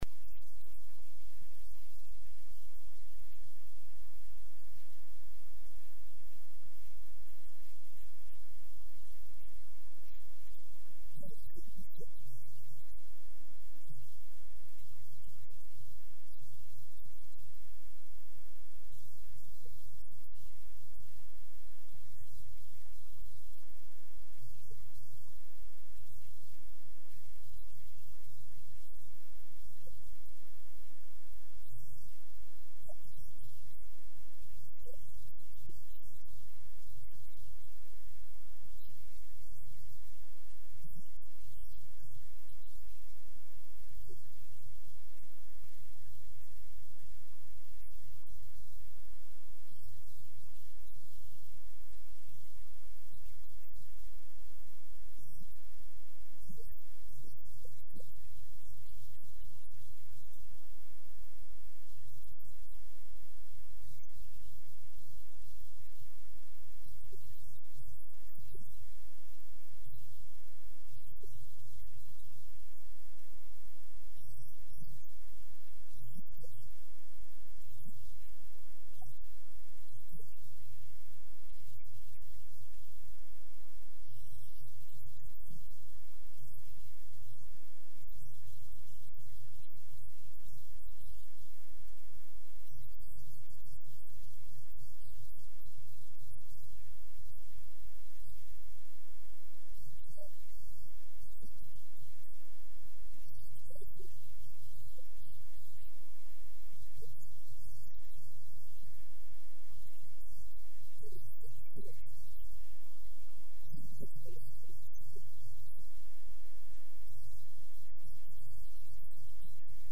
After a long career in the service of the Empire, Richard Luce was appointed Governor of Gibraltar over the period 1997 to 2000. In this extract from his interview he talks about the challenges of taking up the appointment, the complexities of establishing a self-determining overseas territory and his love for the people of Gibraltar. The original interview is in the BECM sound archive at Bristol City Museums Archives.